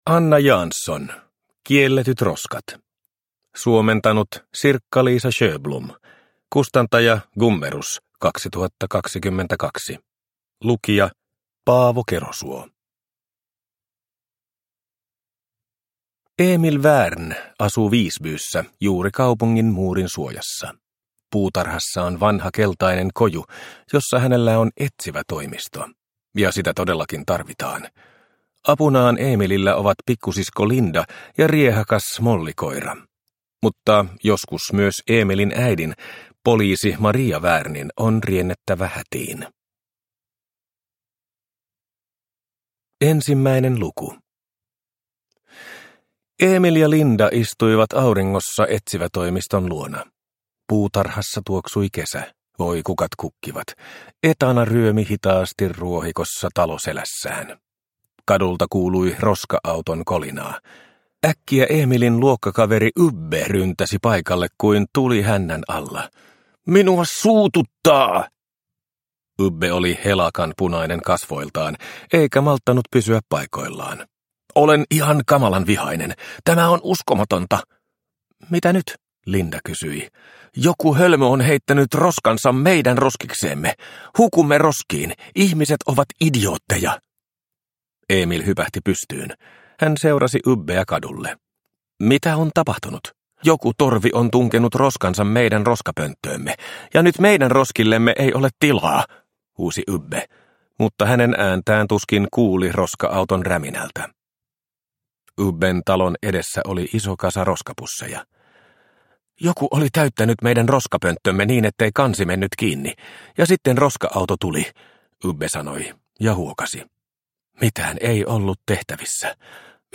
Kielletyt roskat – Ljudbok – Laddas ner